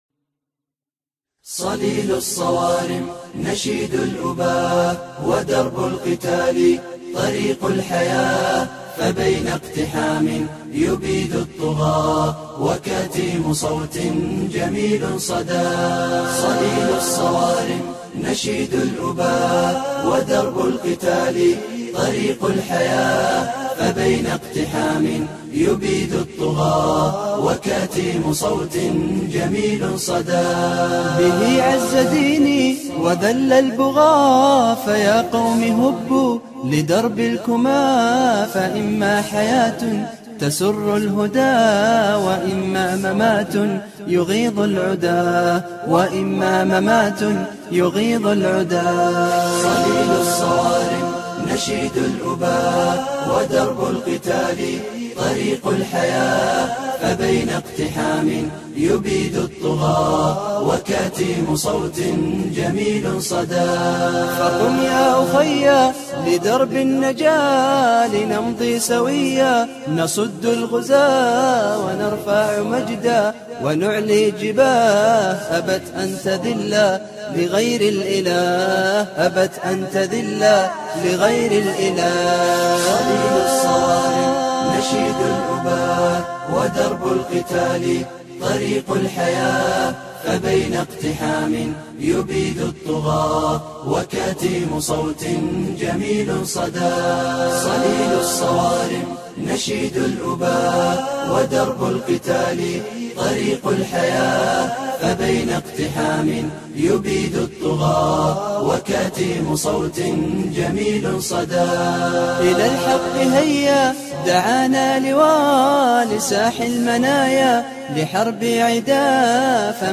nasheed.ogg